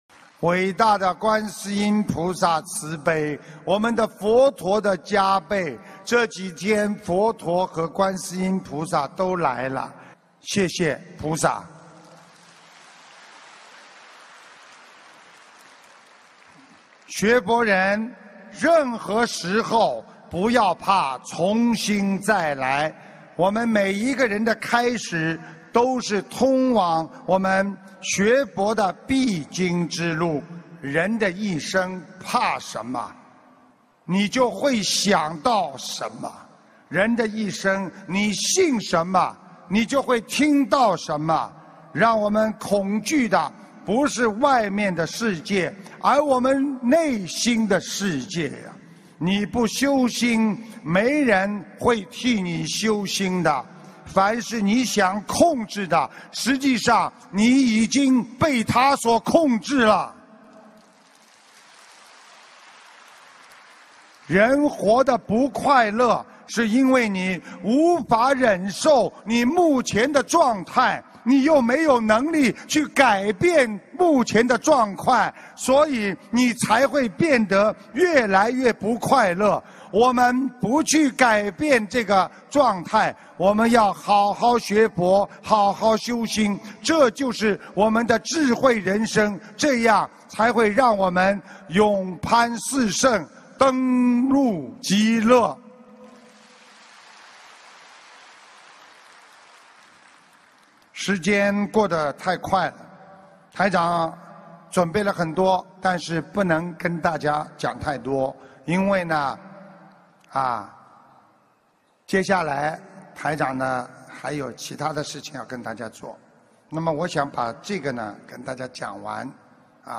音频：四个20岁左右的青年·师父讲小故事大道理